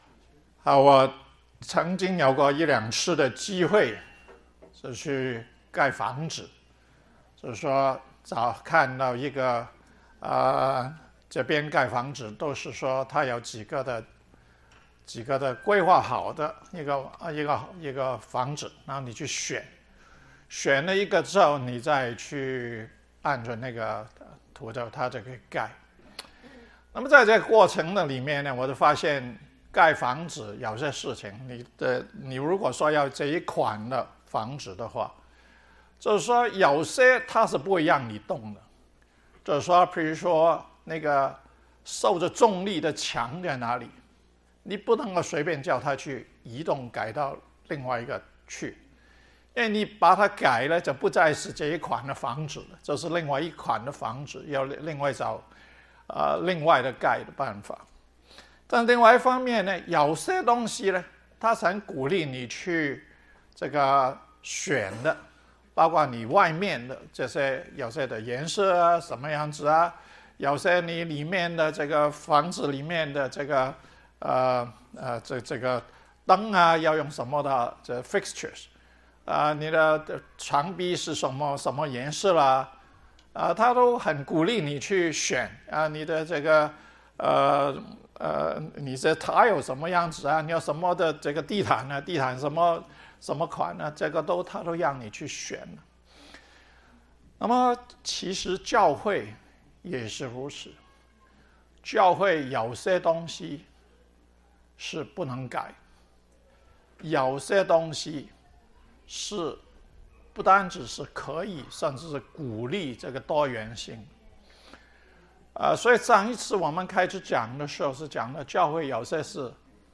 建造教會的生活: “各”的操練： 細讀以弗所書 – 第十七講